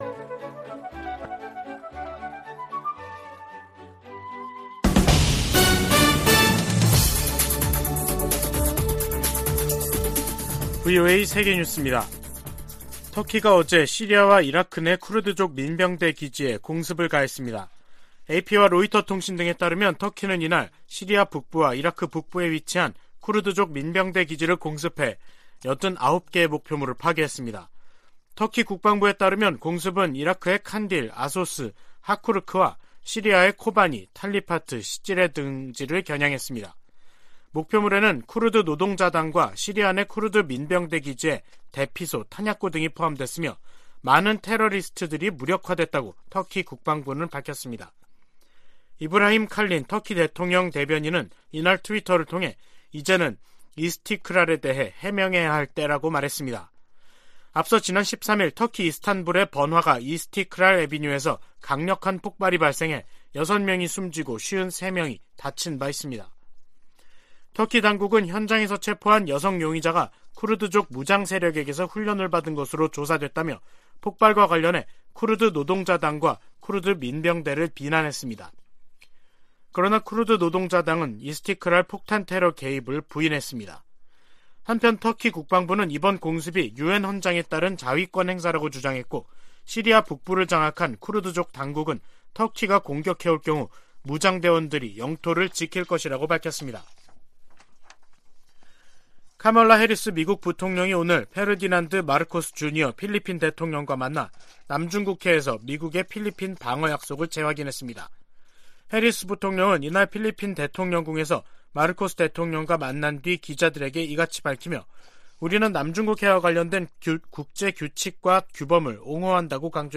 VOA 한국어 간판 뉴스 프로그램 '뉴스 투데이', 2022년 11월 21일 3부 방송입니다. 김정은 북한 국무위원장은 최근의 대륙간탄도미사일 시험발사를 현지 지도하며 대륙간 탄도미사일 부대를 처음 언급했습니다. 북한의 장거리 탄도미사일 발사가 미국 본토에 대한 위협이 되지 않으나 북한이 역내에 제기하는 위협을 우려한다고 백악관 고위 관리가 밝혔습니다.